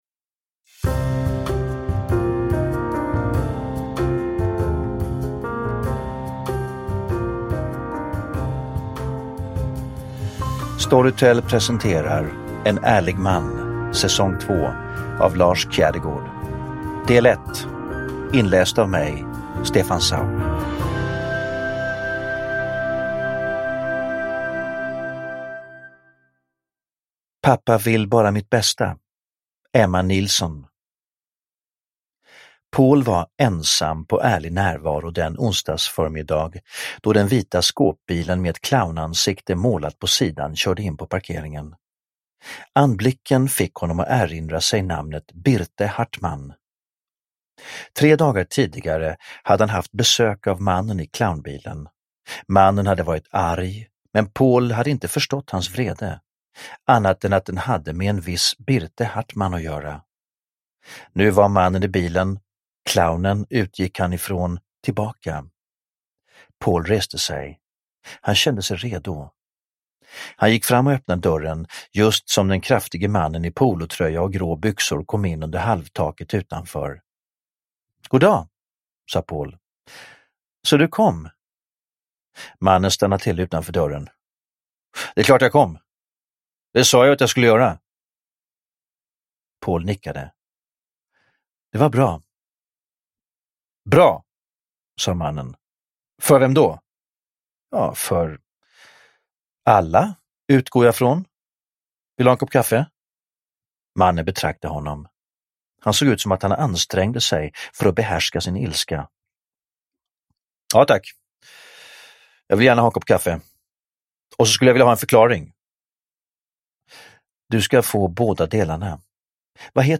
En ärlig man - S2E1 – Ljudbok – Laddas ner
Uppläsare: Stefan Sauk